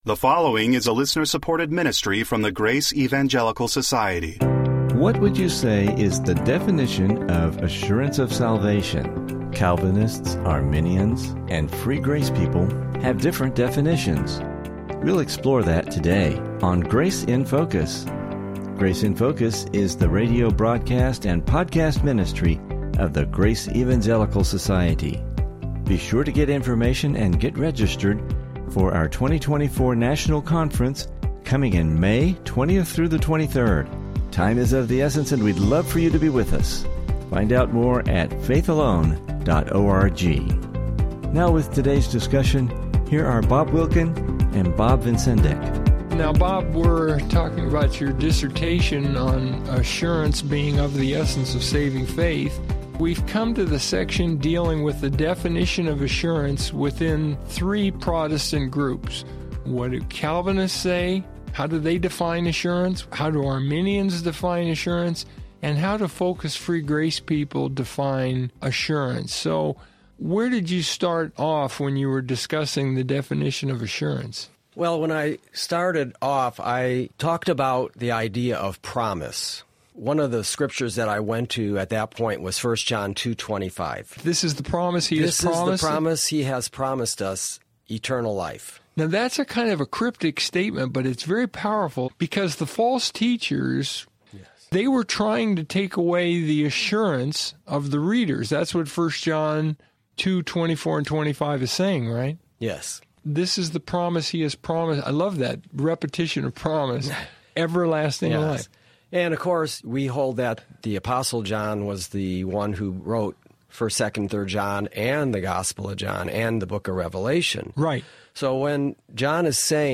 Here’s an interesting discussion concerning this matter.